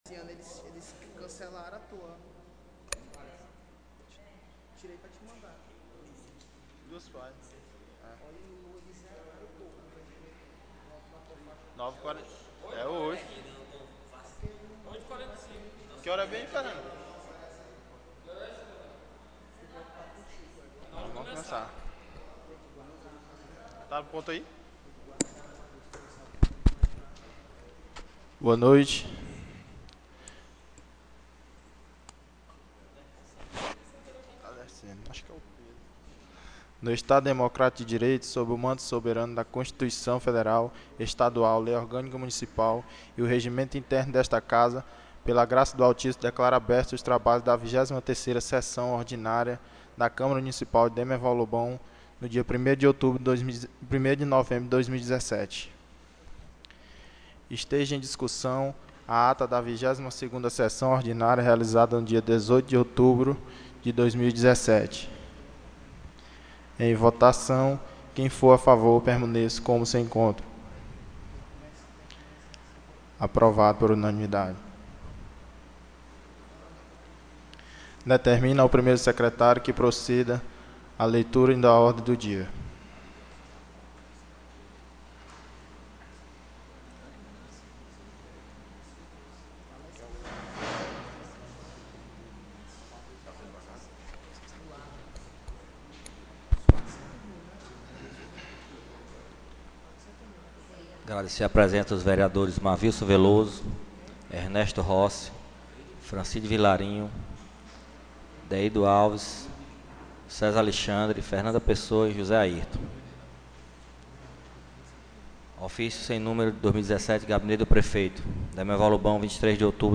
23ª SESSÃO ORDINÁRIA 01/11/2017 — Câmara Municipal de Demerval Lobão